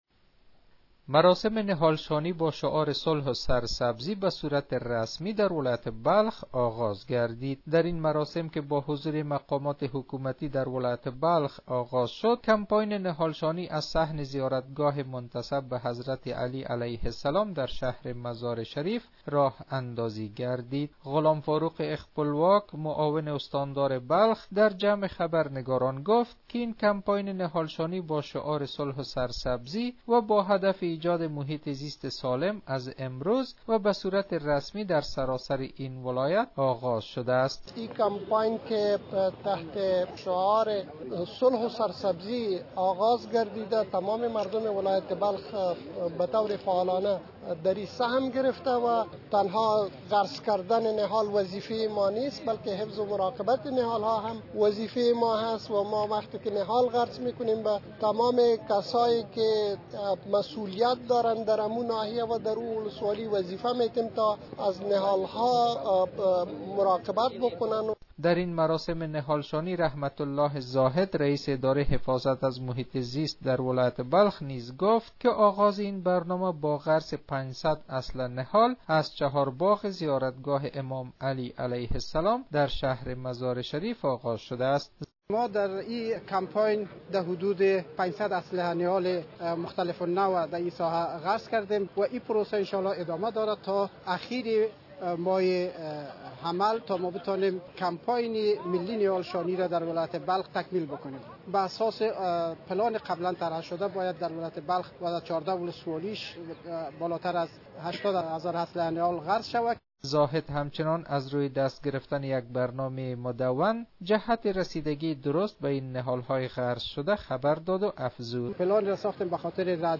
به گزارش خبرنگار رادیو دری دراین مراسم که با حضور مقامات حکومتی در ولایت بلخ آغاز شد کمپاین نهال شانی از صحن زیارتگاه منتسب به حضرت علی (ع ) درشهر مزارشریف راه اندازی شد .